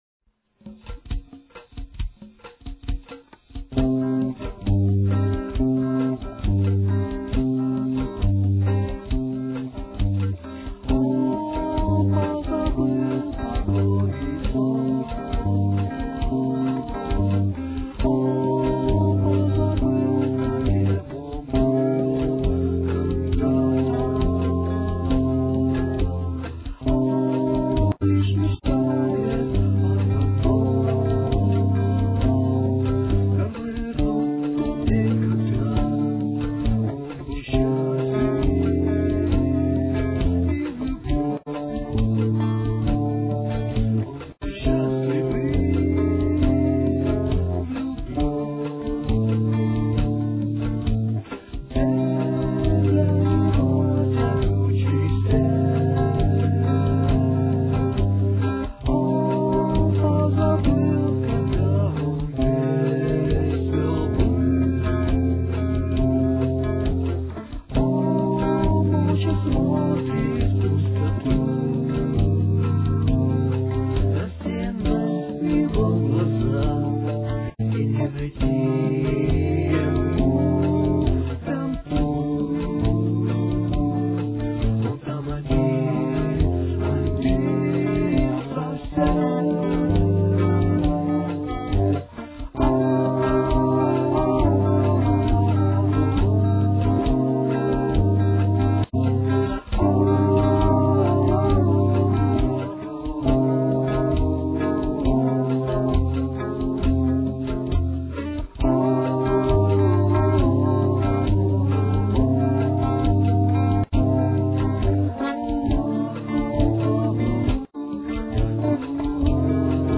on piano